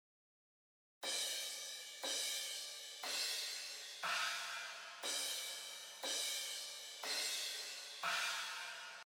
クラッシュ・シンバル
ハイハットはリズムを刻むためのシンバルですが、クラッシュはアクセントをつけるために使われます。
crashcymbal.mp3